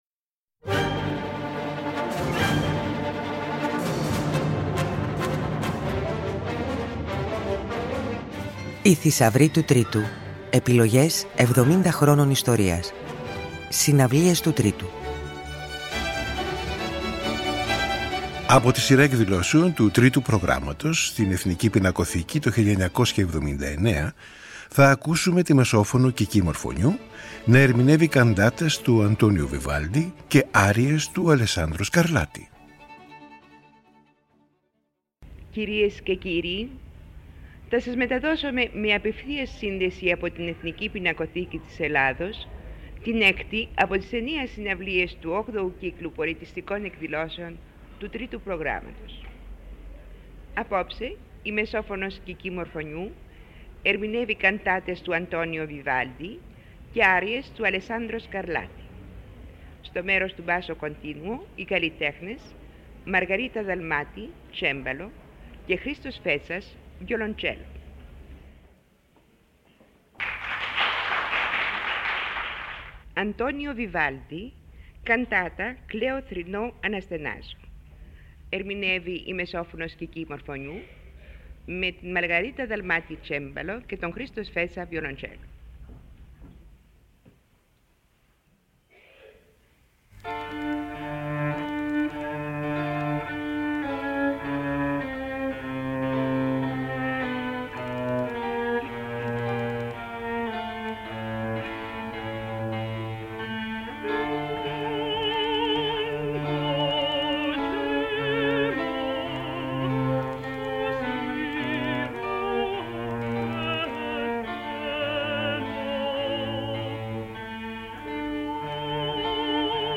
με τη ζωντανή ηχογράφηση μιας ξεχωριστής συναυλίας που δόθηκε το 1979
Ελληνίδα μεσόφωνος
καντάτες
άριες
τσεμπαλίστα
βιολοντσελίστας